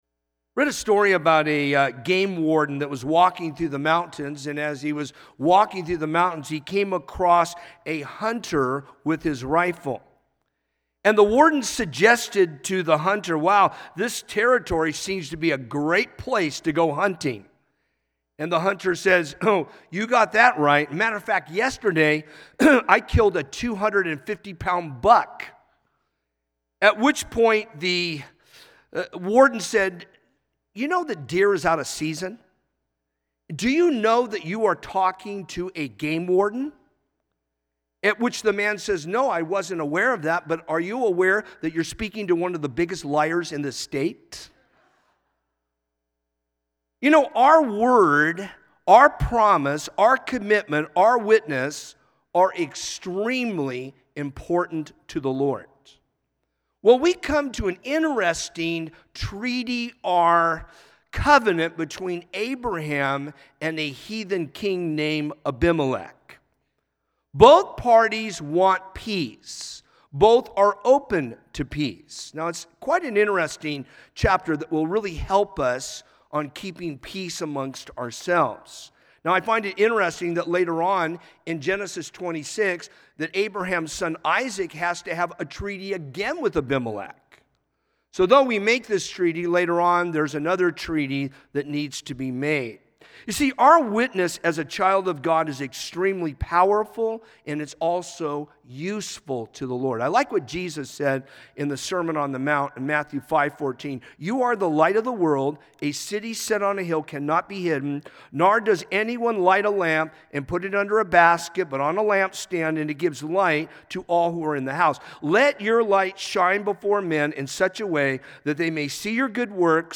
A message from the series "In The Beginning…God."